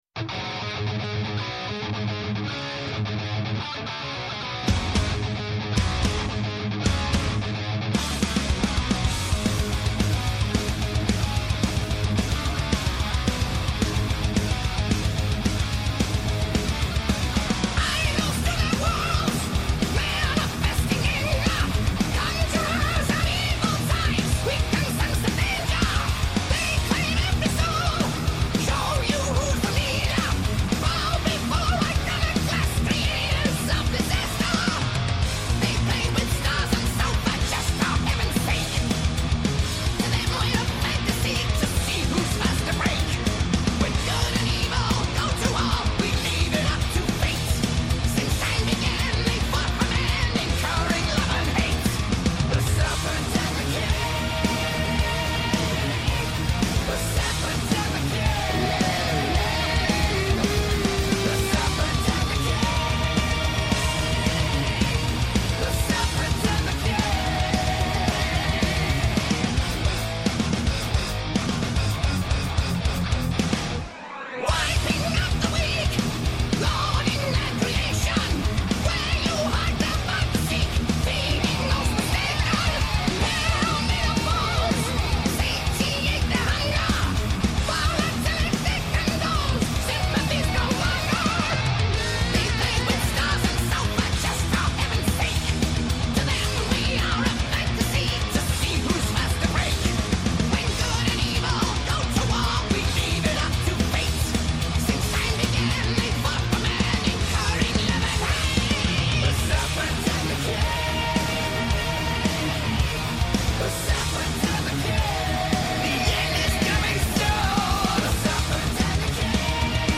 Η μακροβιότερη εκπομπή στο Ελληνικό Ραδιόφωνο!
ΜΟΥΣΙΚΗ